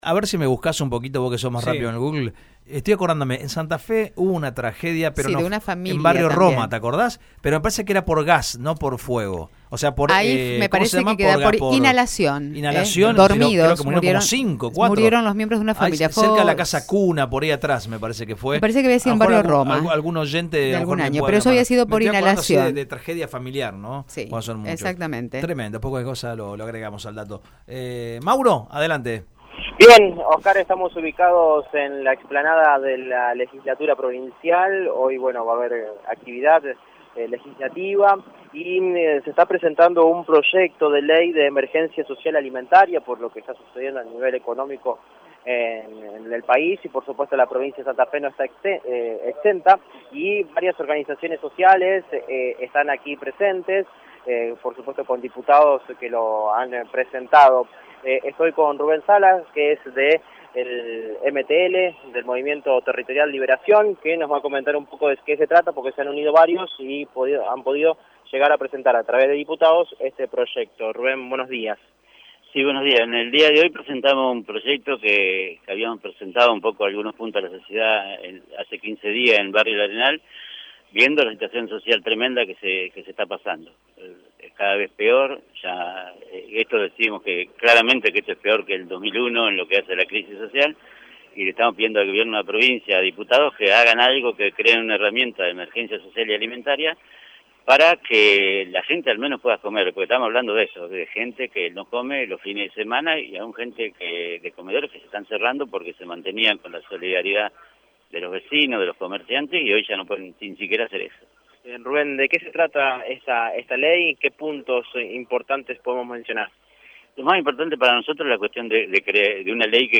En la explanada de la Legislatura de la Provincia, se presentó un proyecto de Ley de Emergencia Social Alimentaria con el objetivo de palear la crisis económica en los sectores más vulnerables.